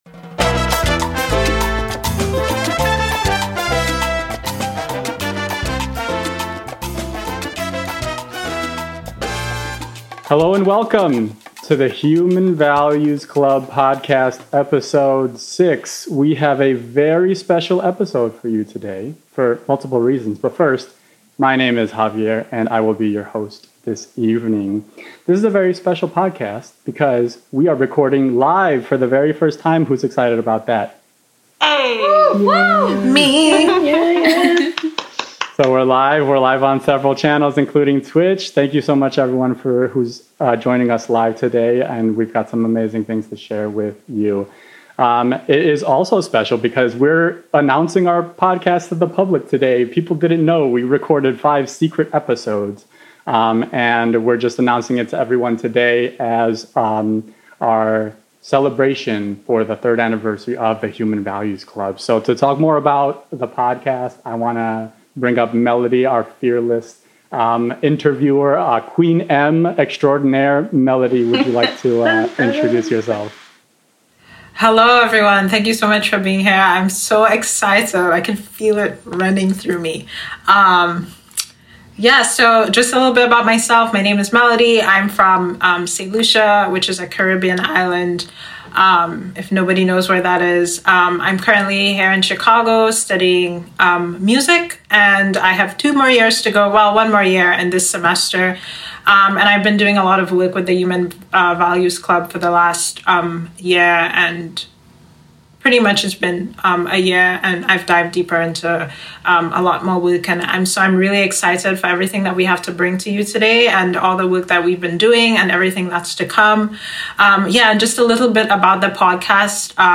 On this live podcast recording on 2/12/21 we celebrated the 3rd anniversary of the Human Values Club organization. We spend time talking about the philosophy of education in human values. We recounted our origin story as well as shared new initiatives for 2021.